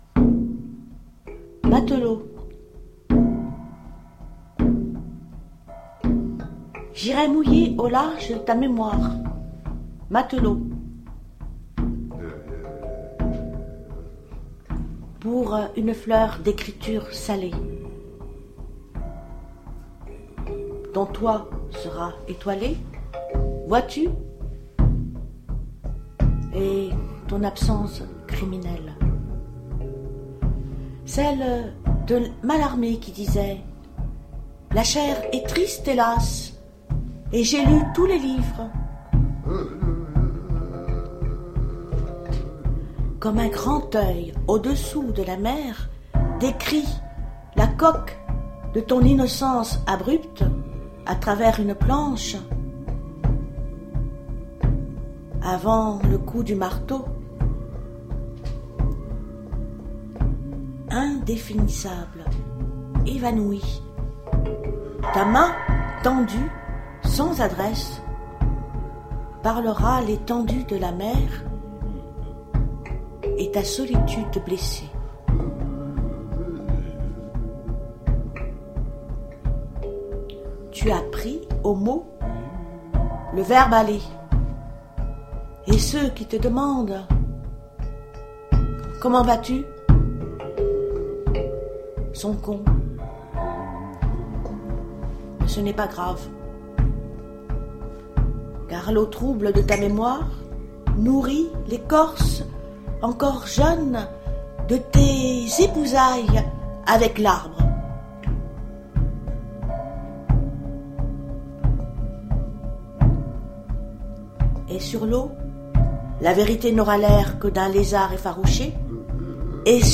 SITAR – PERCUSSION DIJIRIDOO, GUIMBARDE
FLUTE, PERCUSSION, BOL TIBETAIN
Improvisation musicale